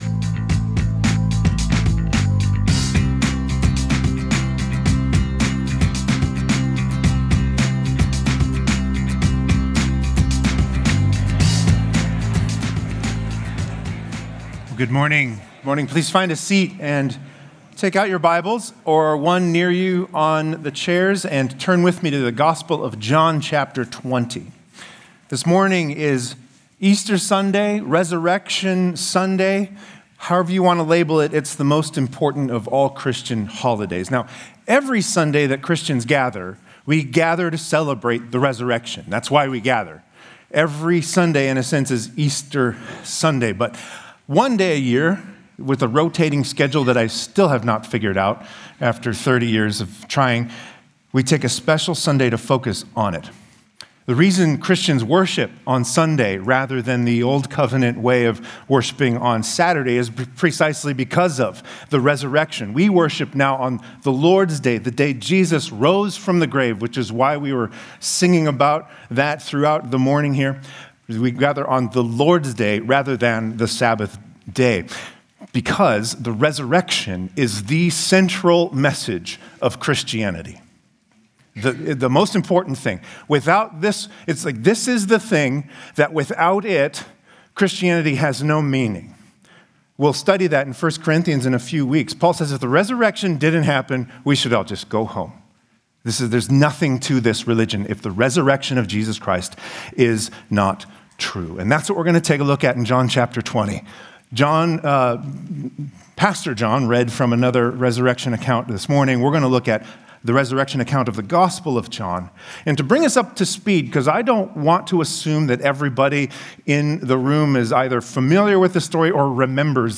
Stonebrook Sunday AM